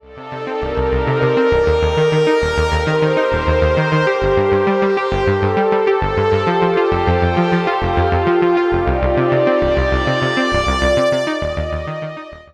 While scanning various tutorial videos on the web, it seemed like the demo music all sounded like this.
polydemo.mp3